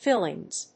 /ˈfɪlɪŋz(米国英語)/